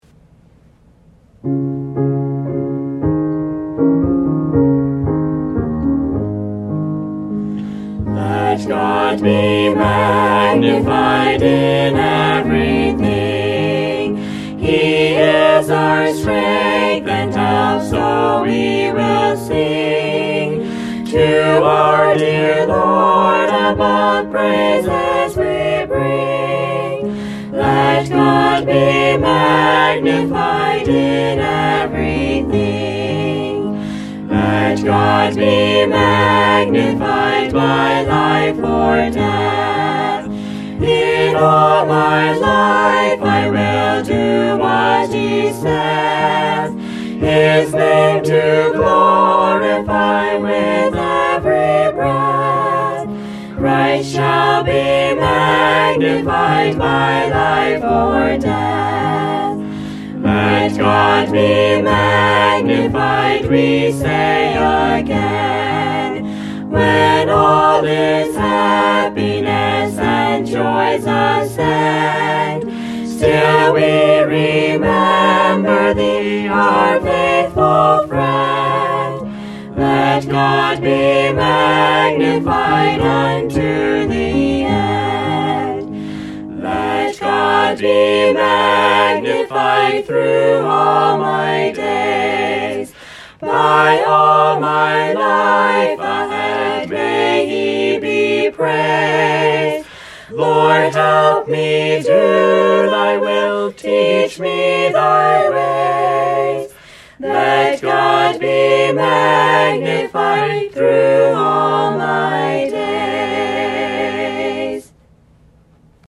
Let-God-Be-Magnified-quintet.mp3